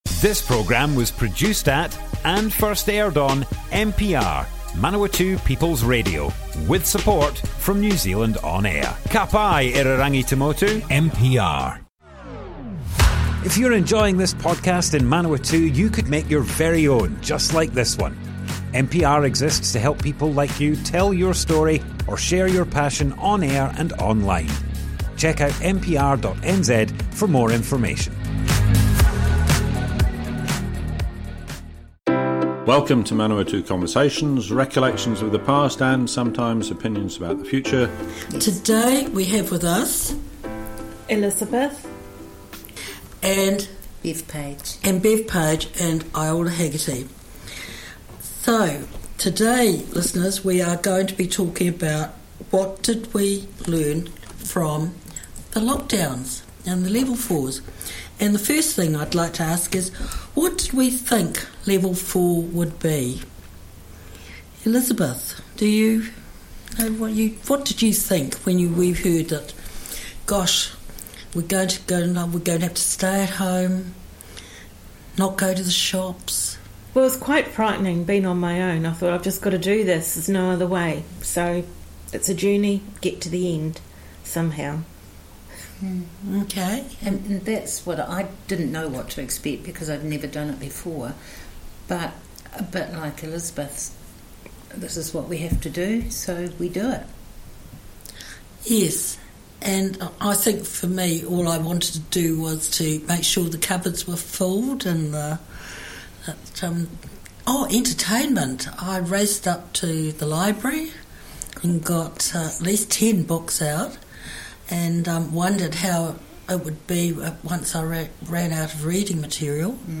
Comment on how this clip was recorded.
Manawatu Conversations More Info → Description Broadcast on Manawatu People's Radio, 28th June 2022.